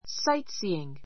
sáitsiːiŋ
（ ⦣ gh は発音しない）